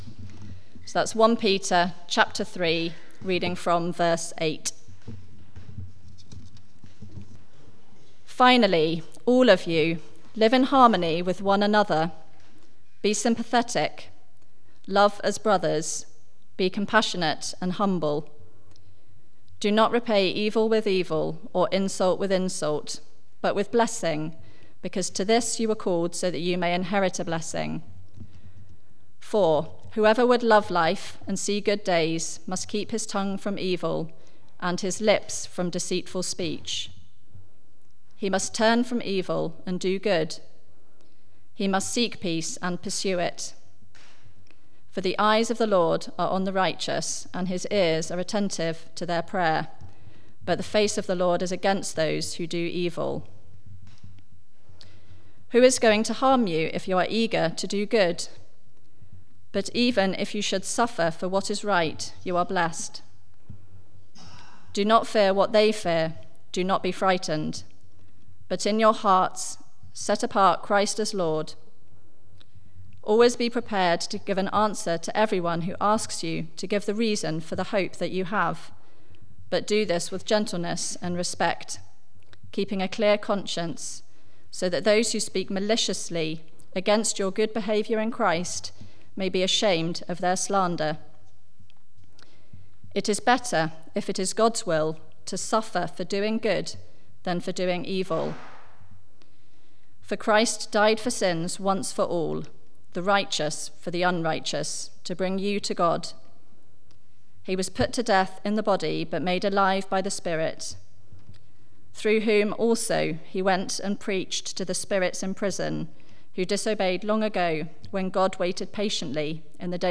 Media for Barkham Morning Service on Sun 30th Jun 2019 10:00
Theme: Godly suffering Sermon